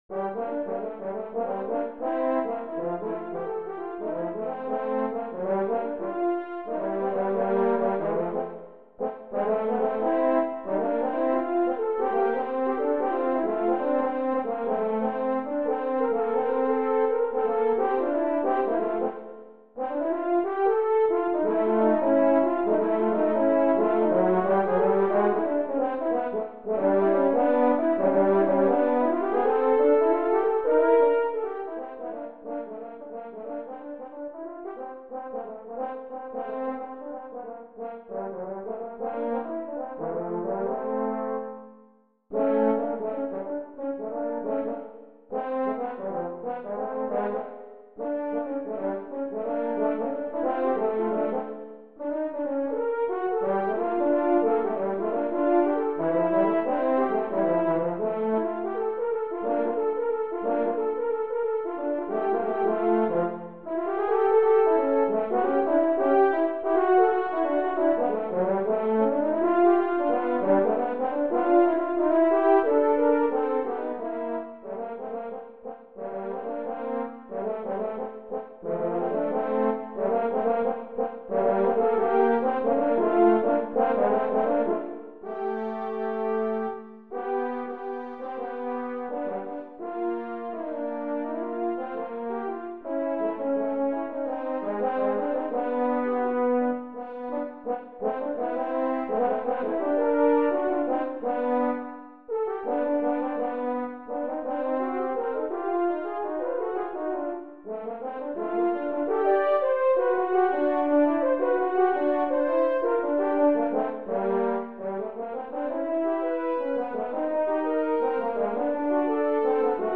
Für 2 Hörner F
Instrumentalnoten für Horn PDF